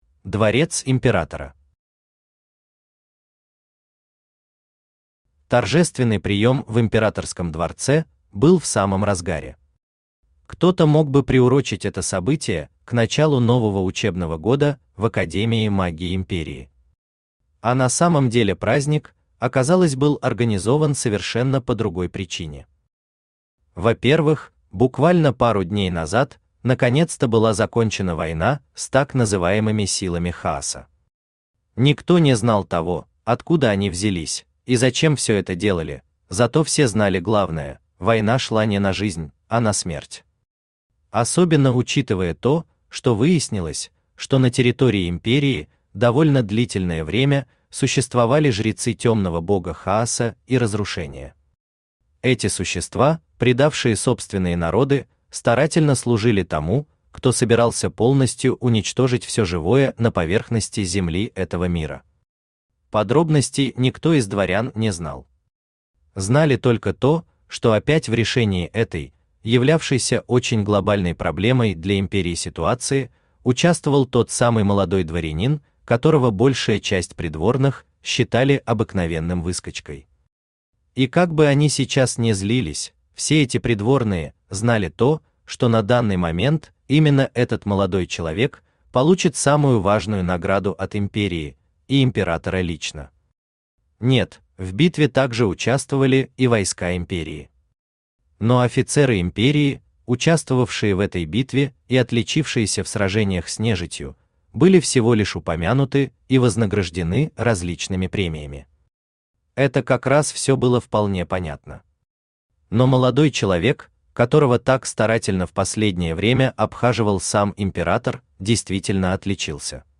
Аудиокнига Гомункул. Великий герцог | Библиотека аудиокниг
Великий герцог Автор Хайдарали Усманов Читает аудиокнигу Авточтец ЛитРес.